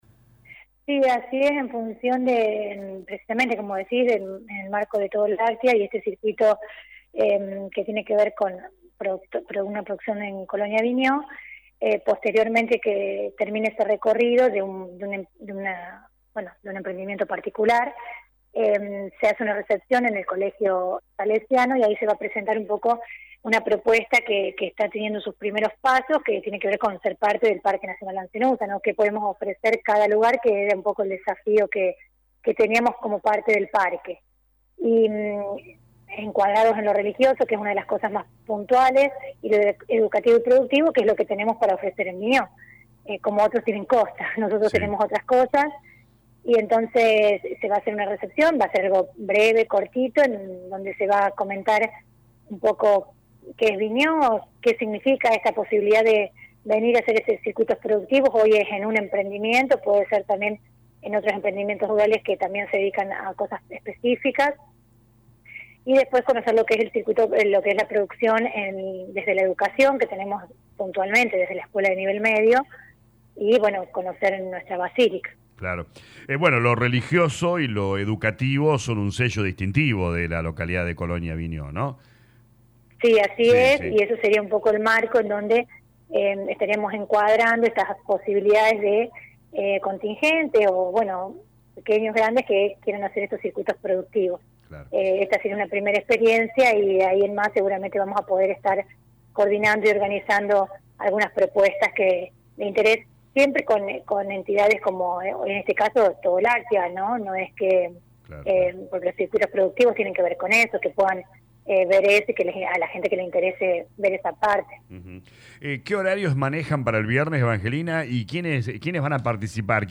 La intendente Lic. Evangelina Vigna habló con LA RADIO 102.9 sobre la actividad del viernes.